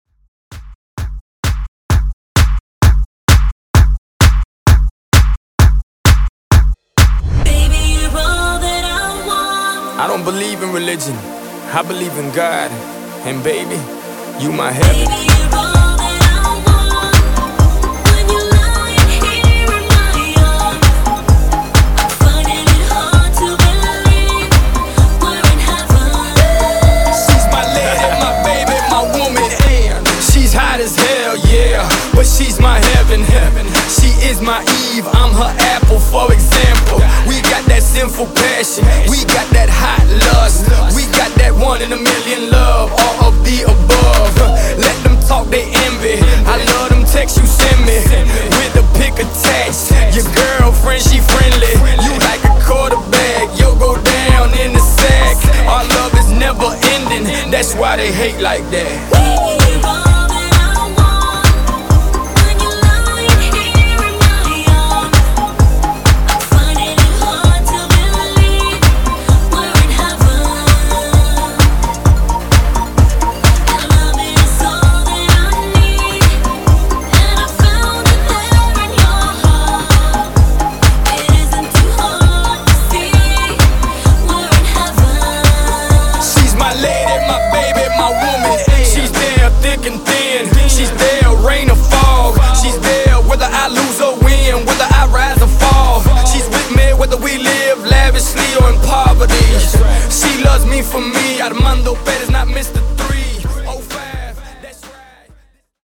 Genre: MASHUPS Version: Clean BPM: 124 Time